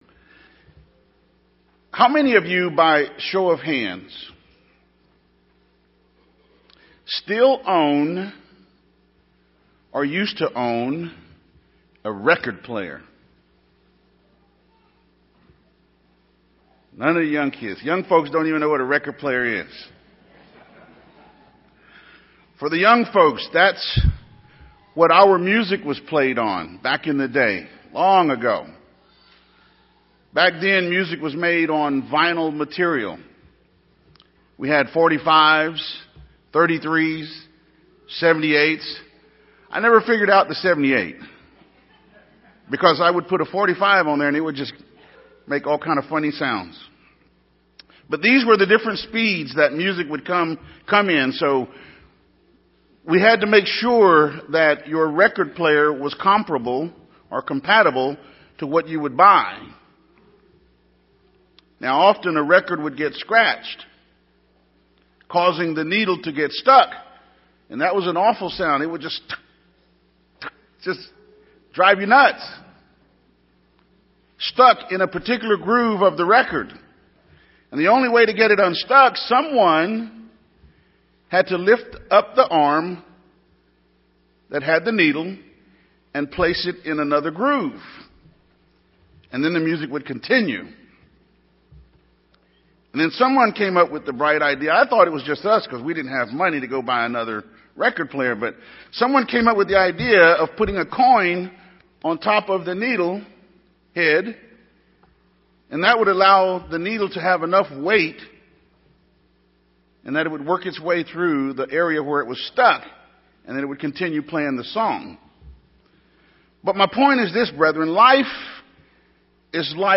Sermons
Given in San Antonio, TX